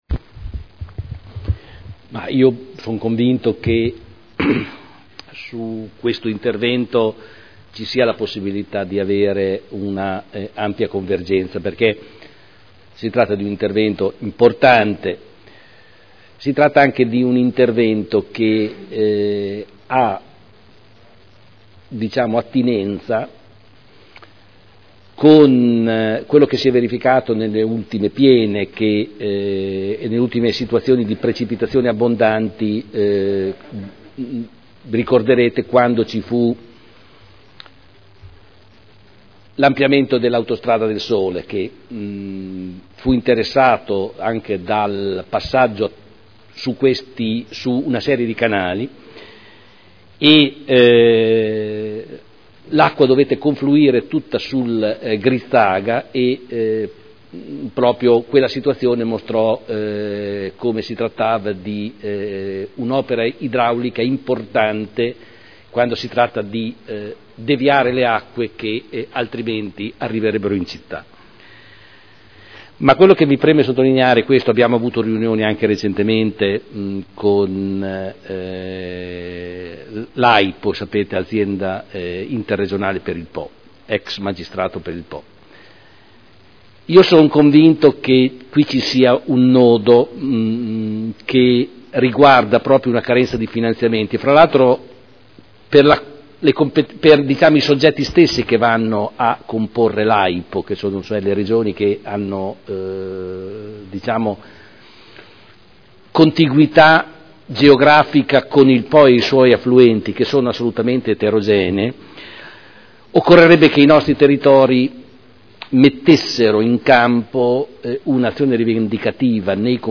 Giorgio Pighi — Sito Audio Consiglio Comunale
Intesa ai sensi dell’art. 16 bis L.R. 37/2001 tra Comune di Modena e Servizio Tecnico dei bacini degli affluenti del Po – Sede di Modena della Regione Emilia Romagna per il progetto esecutivo dei “Lavori di realizzazione di difese spondali e risagomatura a monte del ponte della via Gherbella nel torrente Grizzaga in Comune di Modena”. Dibattito